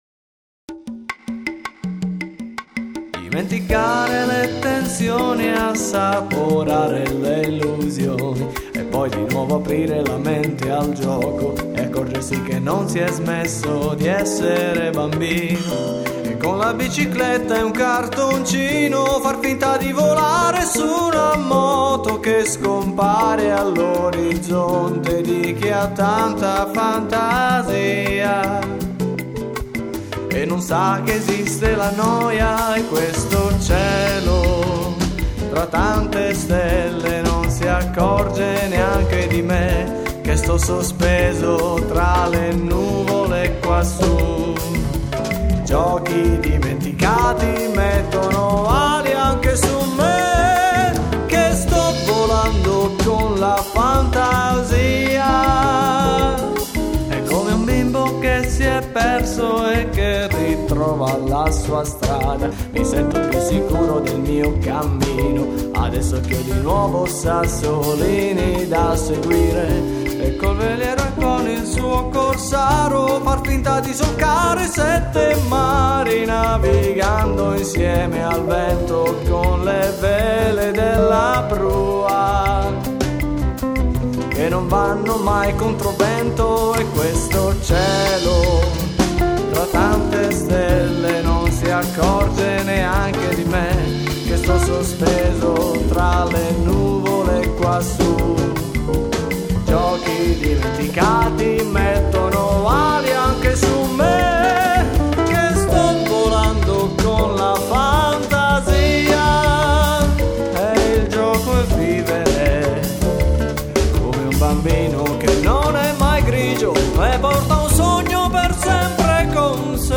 Musicalmente � una bossa nova spensierata.